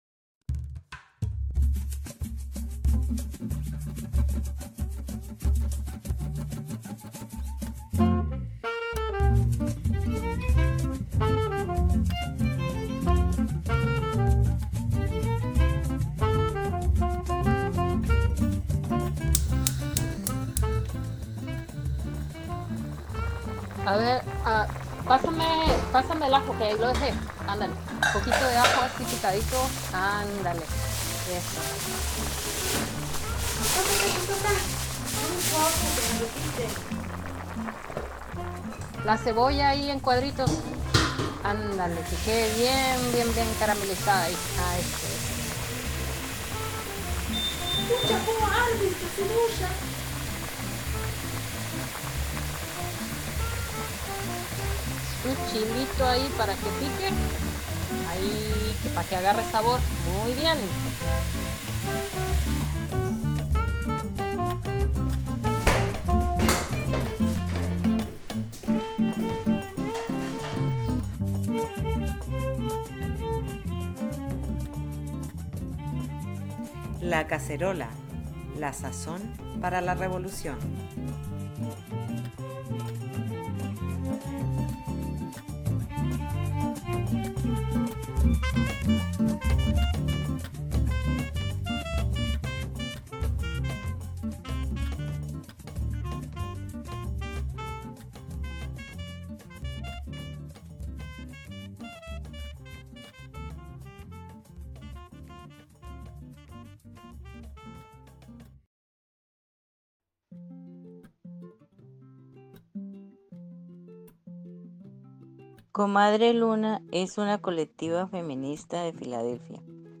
Después escucharemos una entrevista que aborda el racismo en Estados Unidos y en nuestras comunidades latinxs. En las últimas dos secciones nos iremos de paseo, primero al pasado y después al futuro.